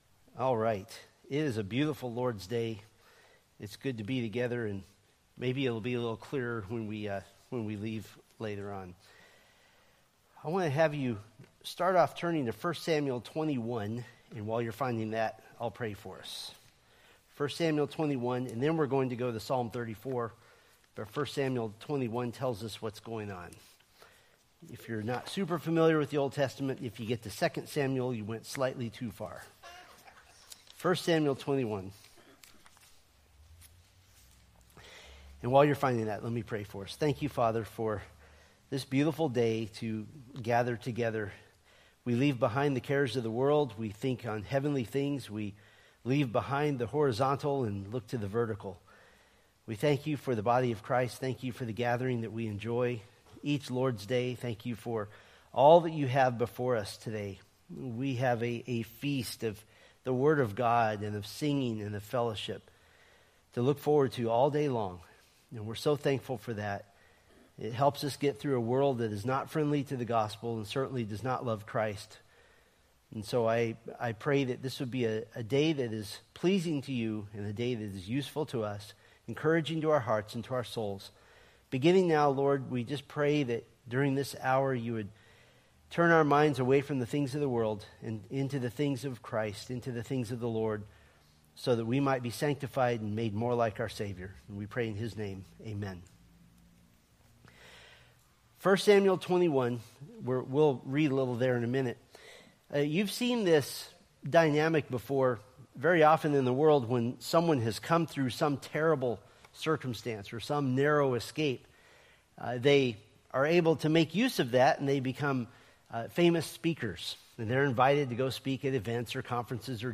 Date: Dec 8, 2024 Series: Psalms Grouping: Sunday School (Adult) More: Download MP3